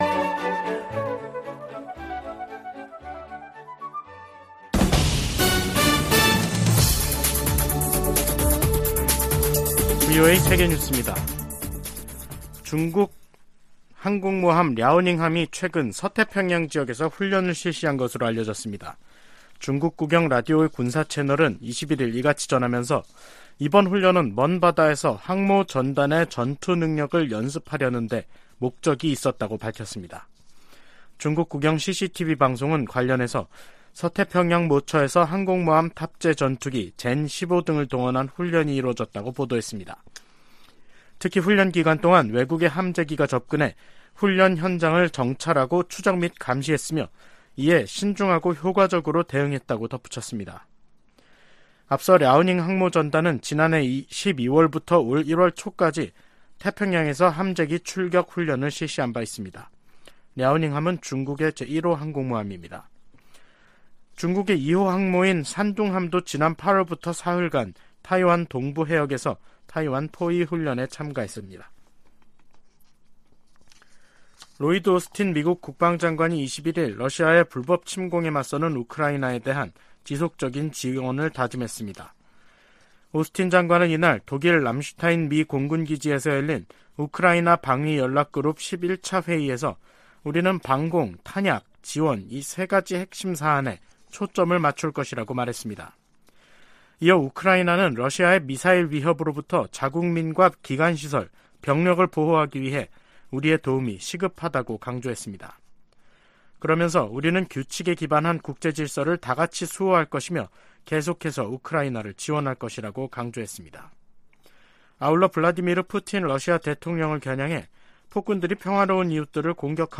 VOA 한국어 간판 뉴스 프로그램 '뉴스 투데이', 2023년 4월 21일 3부 방송입니다. 백악관은 윤석열 한국 대통령의 국빈 방문 기간 중 북한·중국 문제가 논의될 것이라고 밝혔습니다.